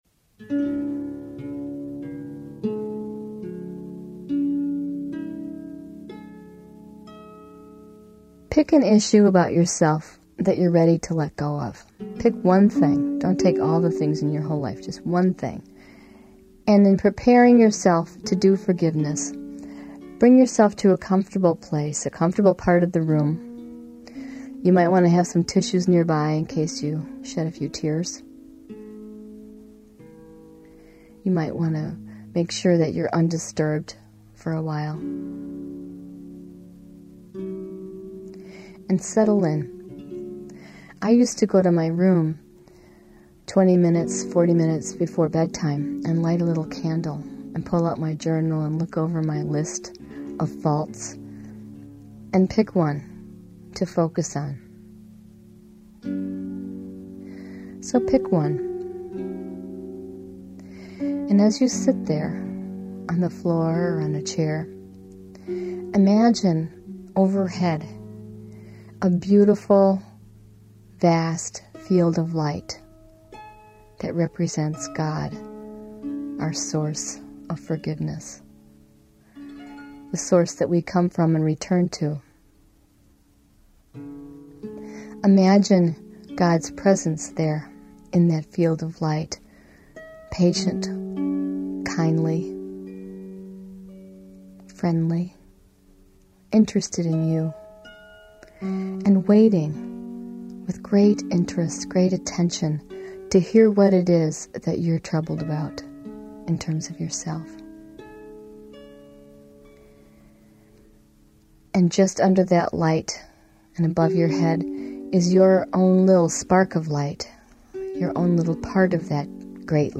MHG-Self-Forgiveness-Exercise.mp3